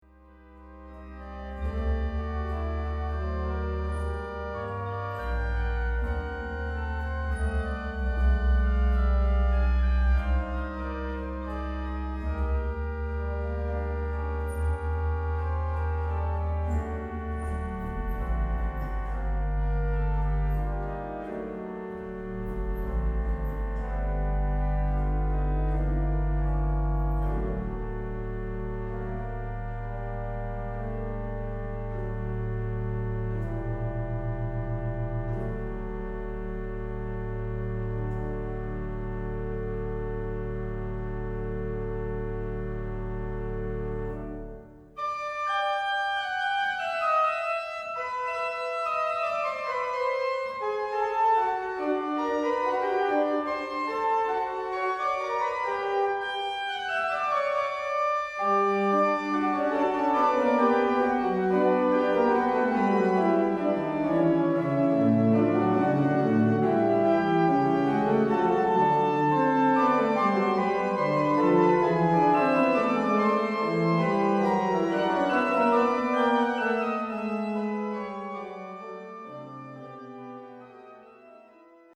ja syvistä
viulu- ja principaläänikerroista